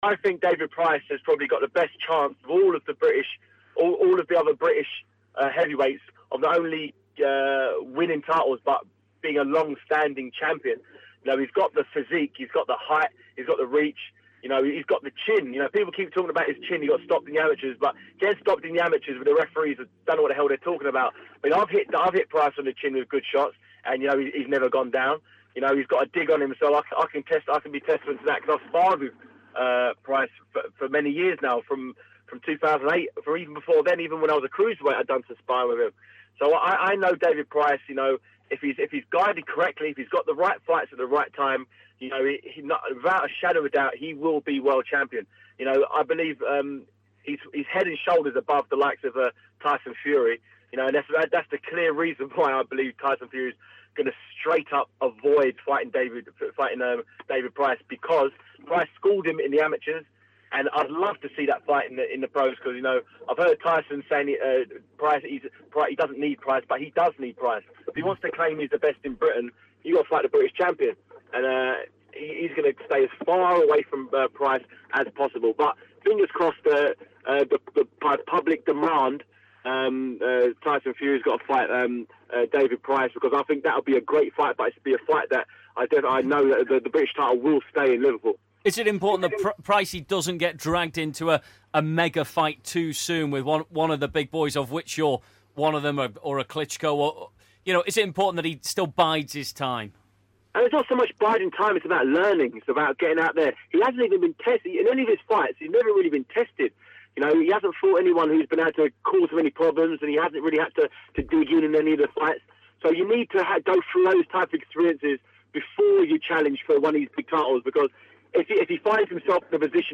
David Haye believes Liverpools David Price will have a World Heavyweight Belt in 2 years time. Haye tells CityTalk 105.9's 'The Gloves Are Off' just how good he thinks Price is.